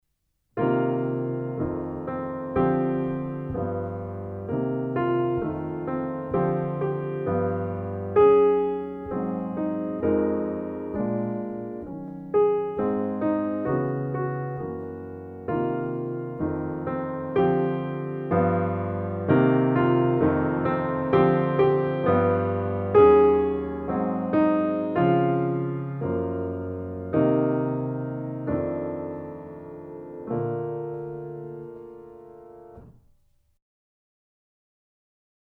Méthode pour Piano